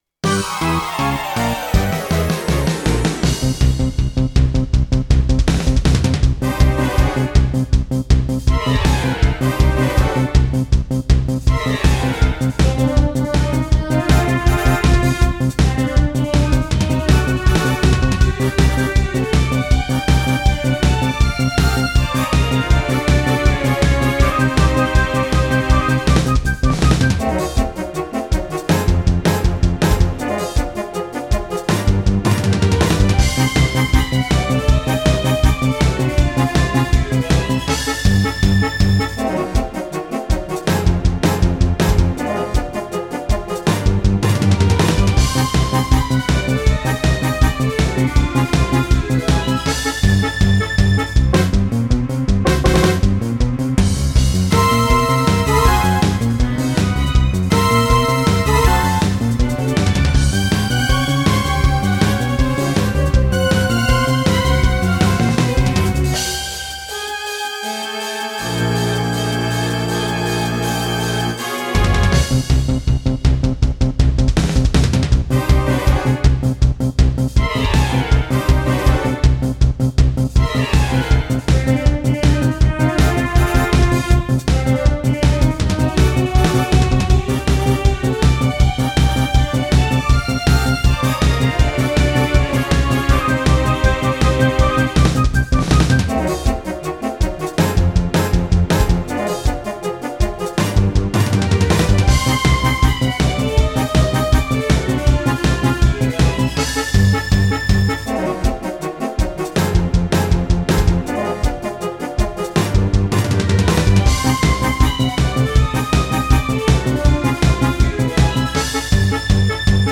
キーは高めです。